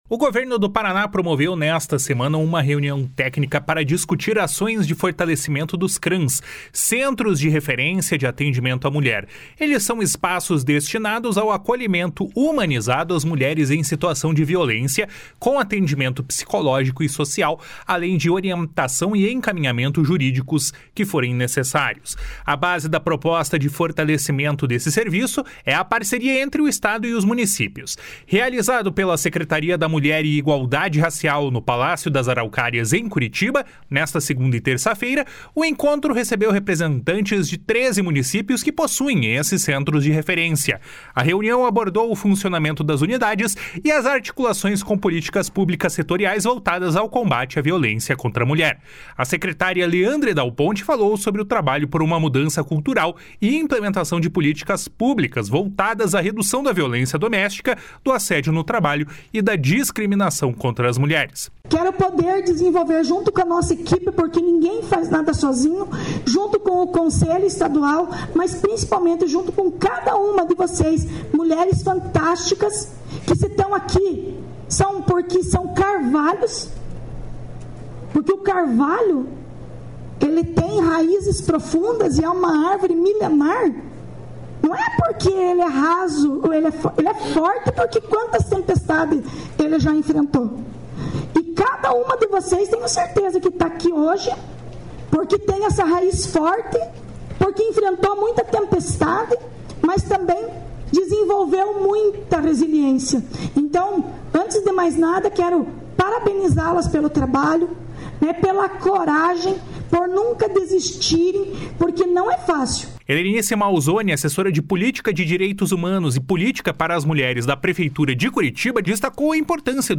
A secretária Leandre Dal Ponte falou sobre o trabalho por uma mudança cultural e implementação de políticas públicas voltadas à redução da violência doméstica, do assédio no trabalho e da discriminação contra as mulheres. // SONORA LEANDRE DAL PONTE //